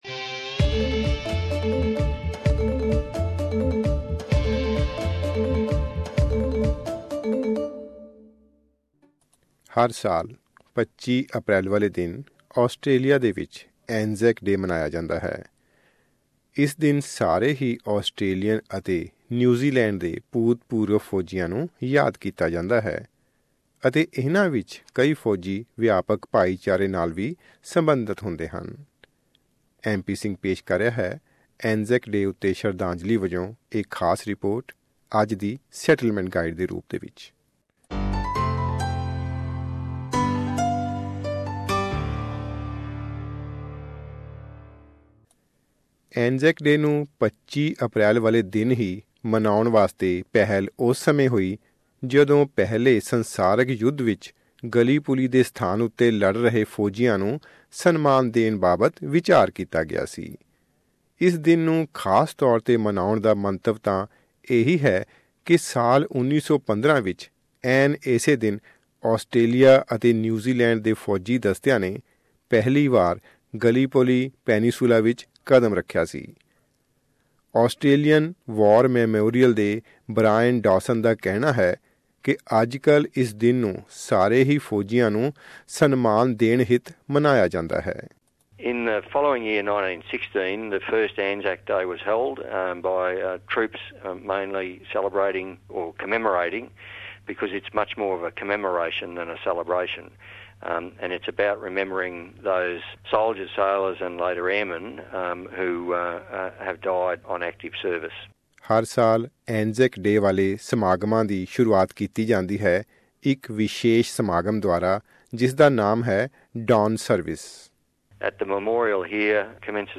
ਐਸ ਬੀ ਐਸ ਪੰਜਾਬੀ